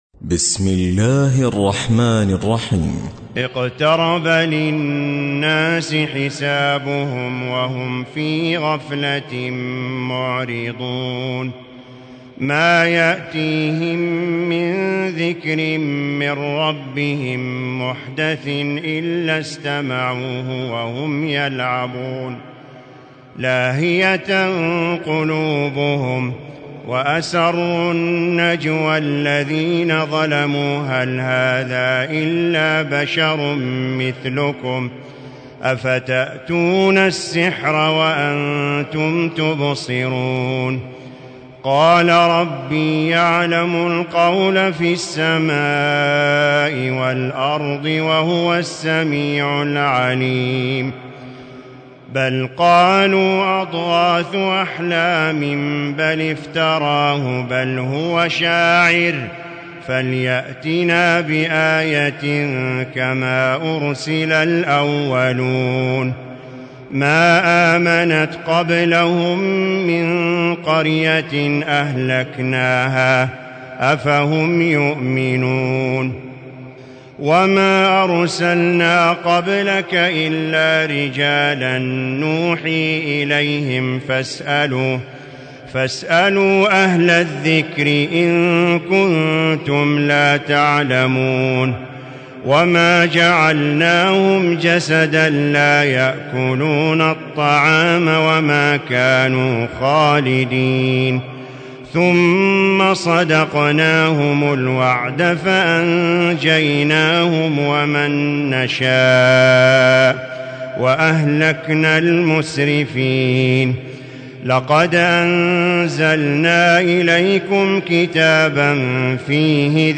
تراويح الليلة السادسة عشر رمضان 1437هـ سورة الأنبياء كاملة Taraweeh 16 st night Ramadan 1437H from Surah Al-Anbiyaa > تراويح الحرم المكي عام 1437 🕋 > التراويح - تلاوات الحرمين